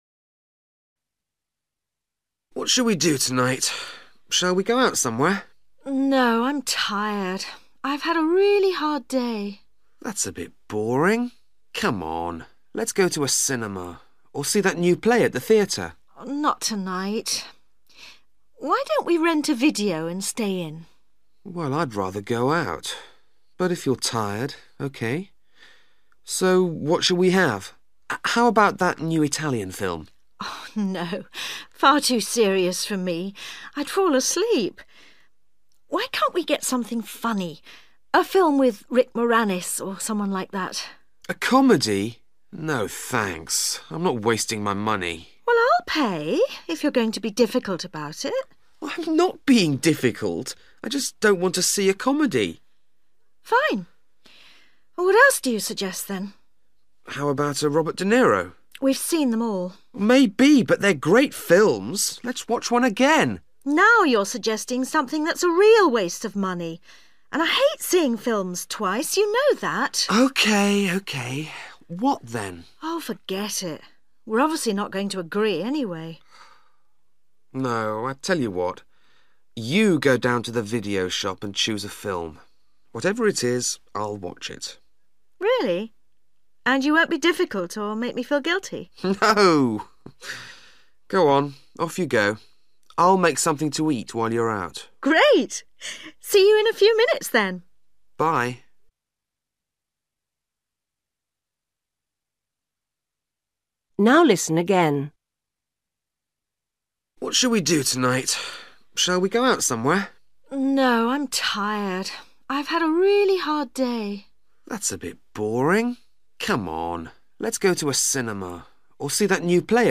You will hear a conversation between a man and a woman at home.